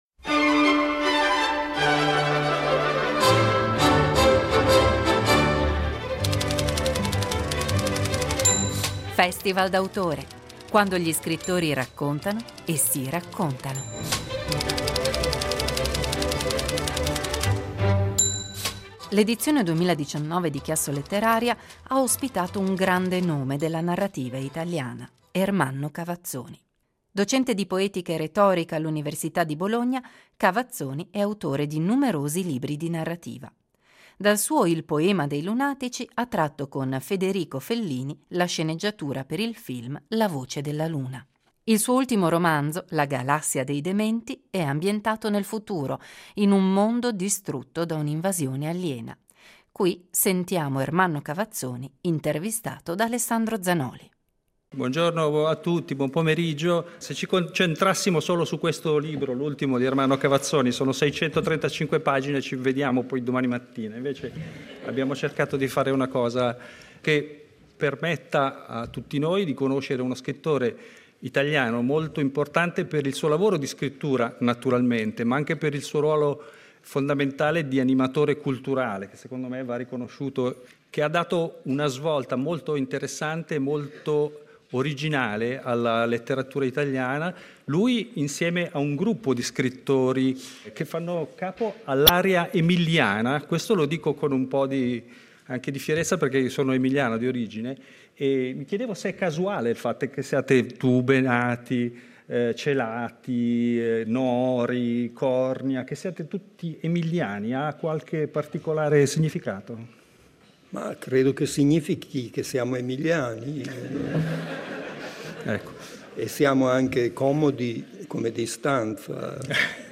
L’edizione 2019 di Chiassoletteraria ha ospitato un grande nome della narrativa italiana: Ermanno Cavazzoni.
Il suo ultimo romanzo La galassia dei dementi è ambientato nel futuro, in un mondo distrutto da un’invasione aliena. Qui lo sentiamo intervistato